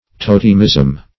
Totemism \To"tem*ism\, n.